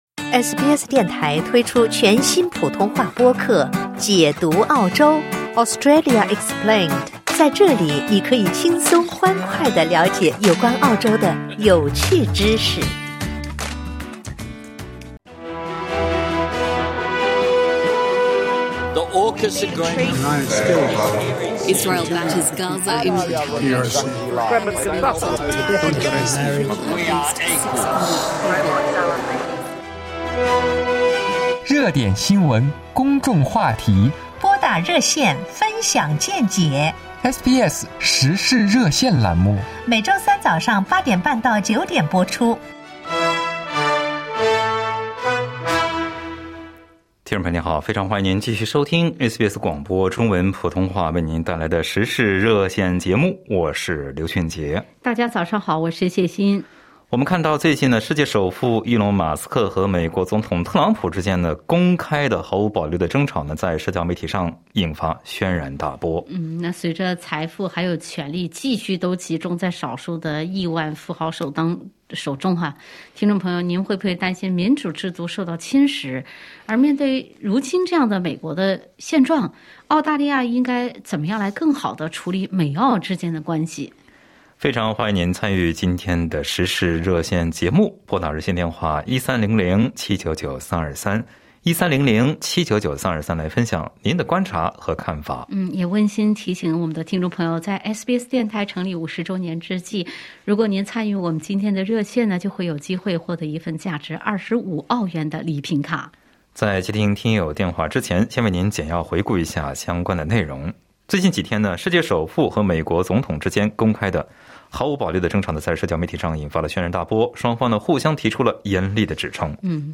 在本期《时事热线》节目中，听友们就美国局势以及澳美关系分享了看法。